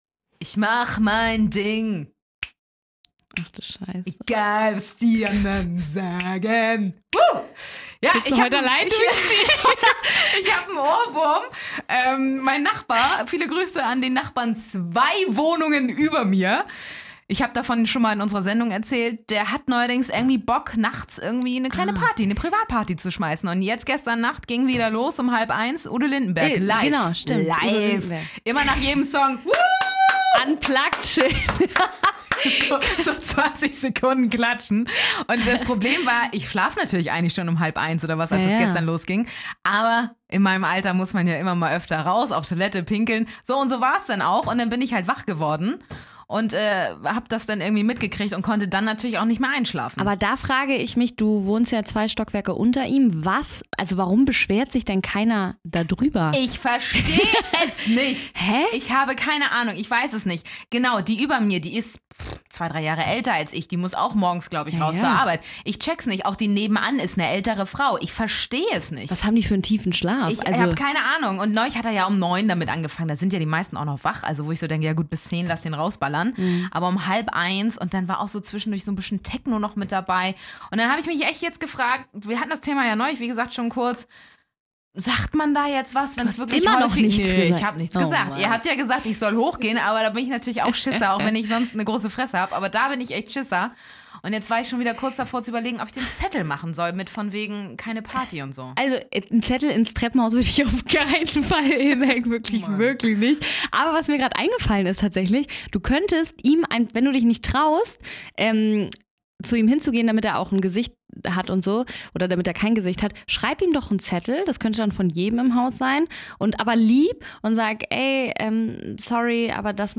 Wir entschuldigen uns für den schlechten Gesang, für die Werbung eines bestimmten Drinks und für unser niedriges Niveau.